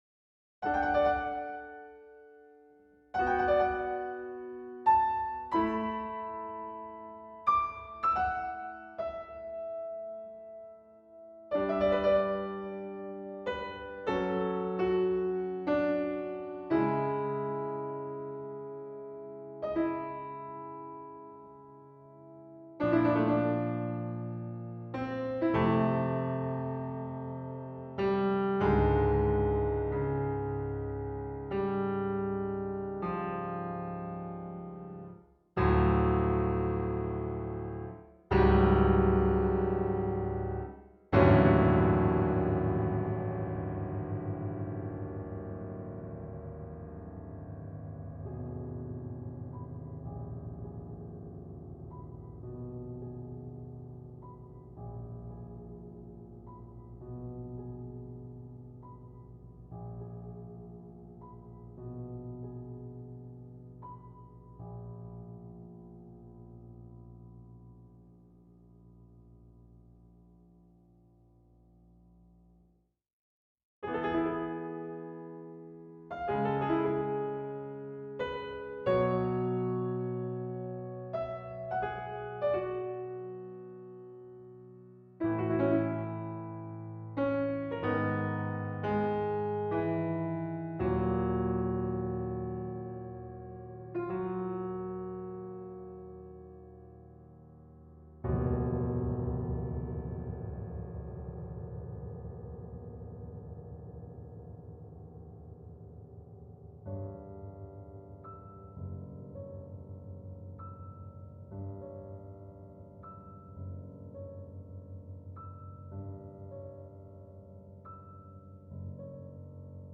piano Duration